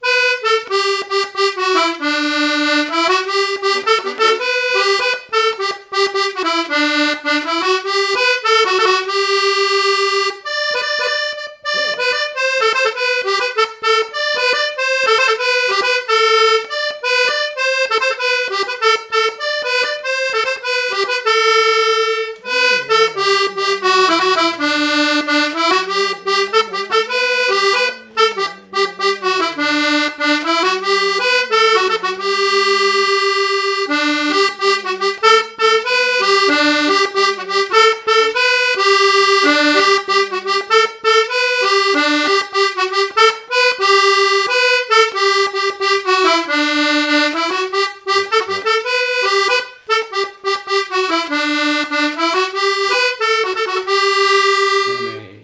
danse : branle : avant-deux
Pièce musicale inédite